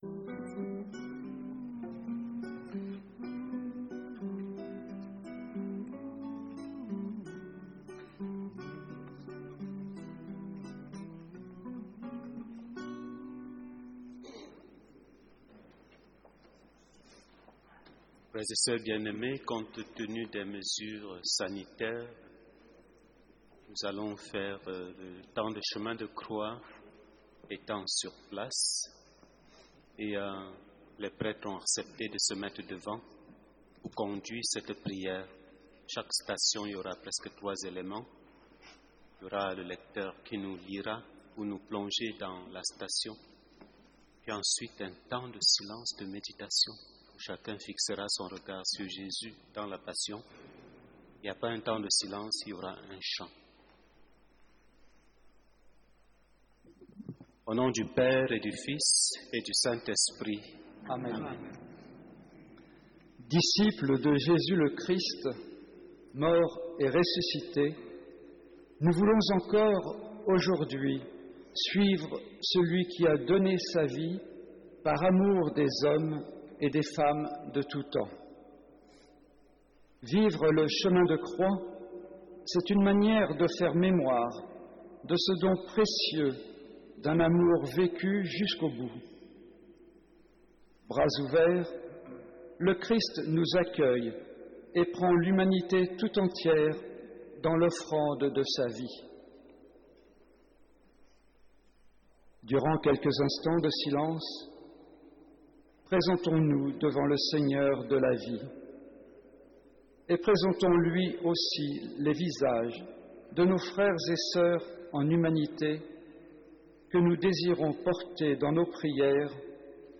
Méditation du Chemin de Croix audio…